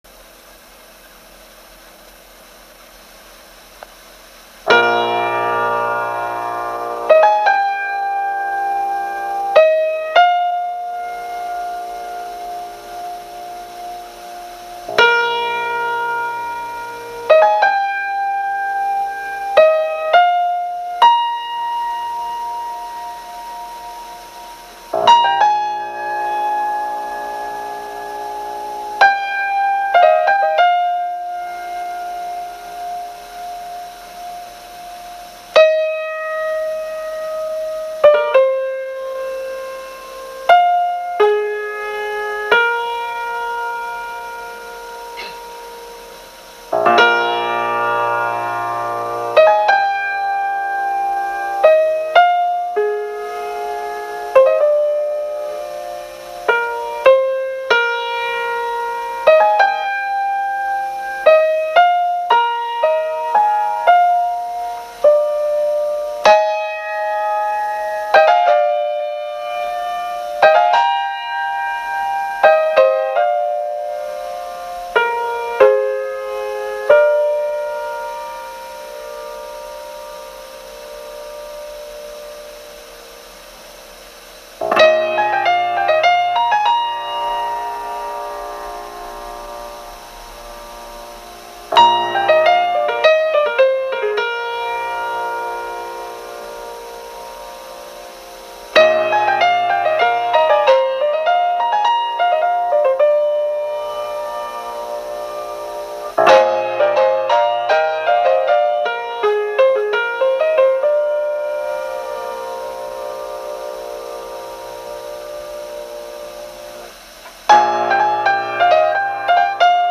Of particular note are his initial ideas for percussion accompaniment, which he stressed he left out of the score since he admitted to having no idea what pipe band-style drumming was all about.
Journey_to_Skye_1987_Don_Thompson_piano.m4a